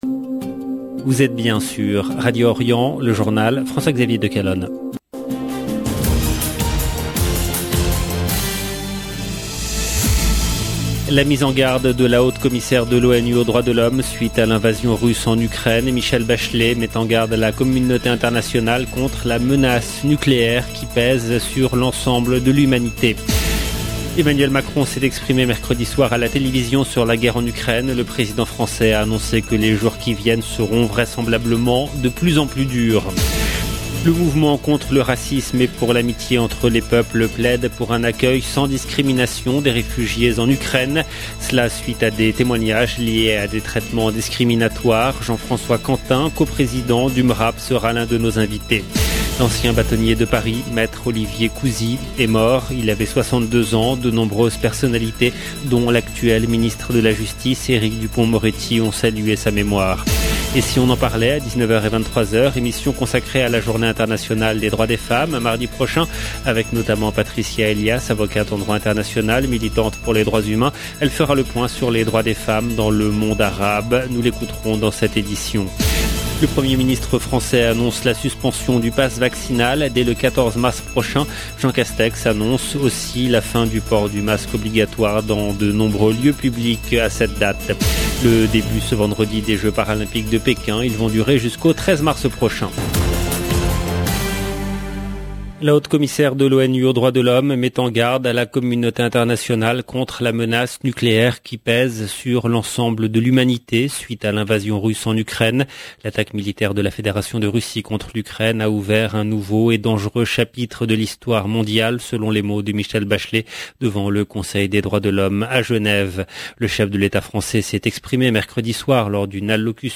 EDITION DU JOURNAL DU SOIR EN LANGUE FRANCAISE DU 3/3/2022